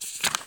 x_enchanting_scroll.5.ogg